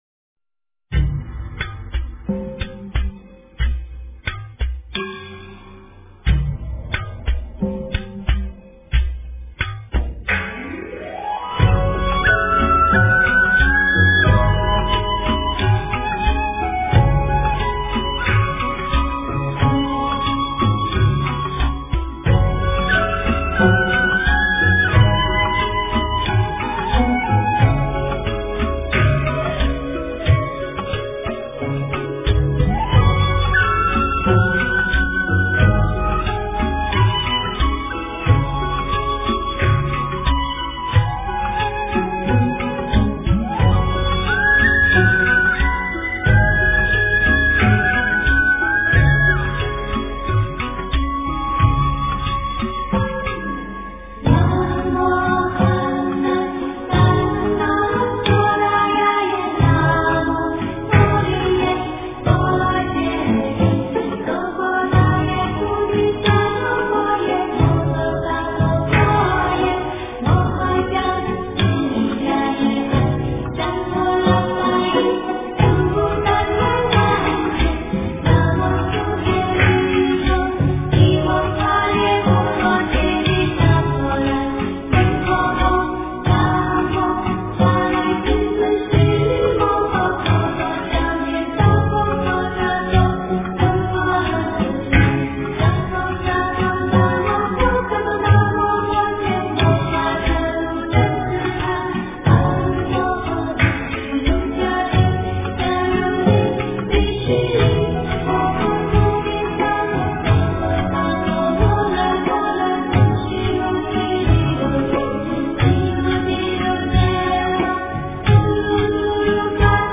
大悲咒 - 诵经 - 云佛论坛